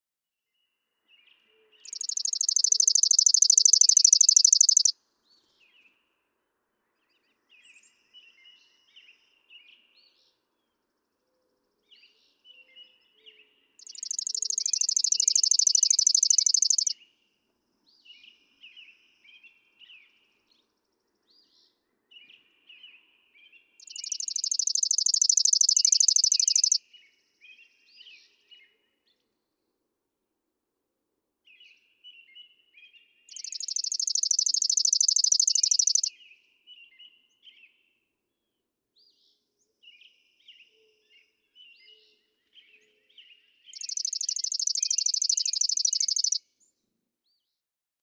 Chipping sparrow
Garland Lodge and Golf Resort, Lewiston, Michigan.
♫196, ♫197—longer recordings from those two neighbors
196_Chipping_Sparrow.mp3